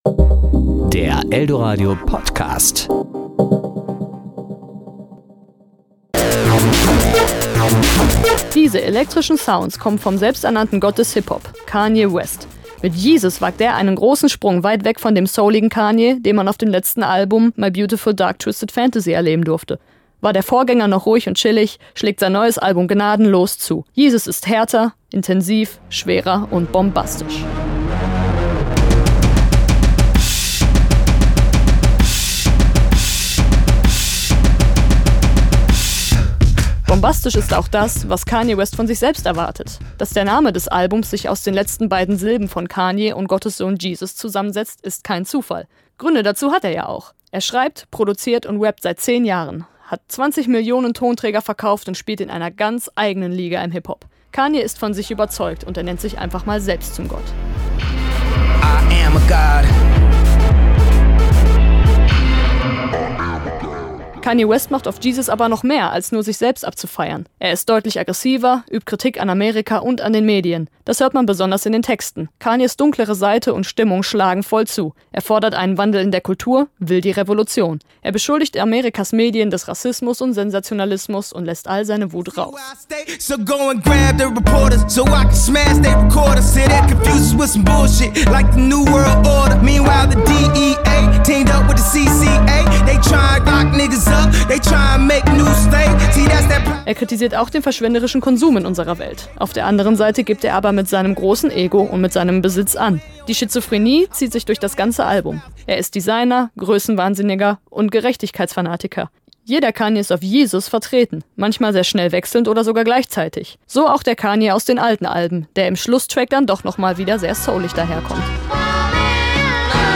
Album-Rezension Kanye West